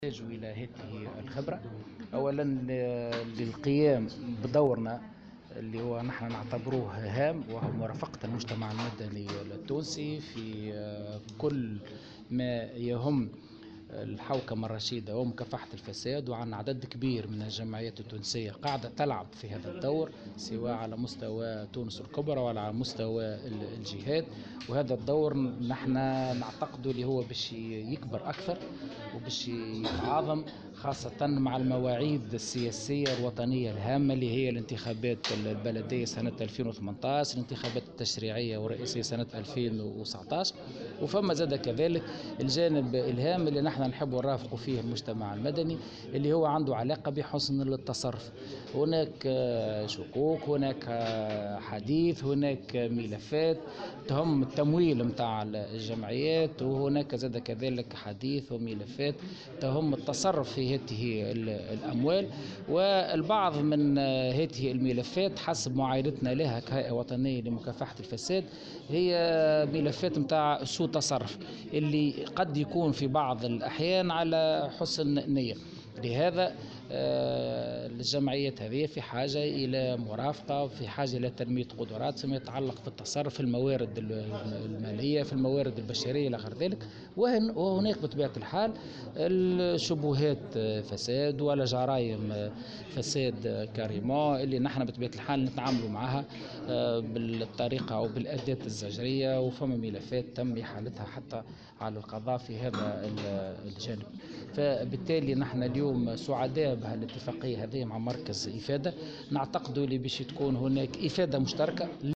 وأضاف في تصريح اليوم لمراسلة "الجوهرة أف أم" على هامش توقيع إتفاقية تعاون بين الهيئة الوطنية لمكافحة الفساد ومركز الإعلام والتكوين والدراسات والتوثيق حول الجمعيات، أن بعض الملفات تتعلق بسوء تصرف قد يكون في بعض الأحيان عن حسن نية، مشيرا إلى أن هذا التعاون يهدف إلى مرافقة الجمعيات وتنمية قدراتها فيما يتعلق بالتصرف في الموارد المالية.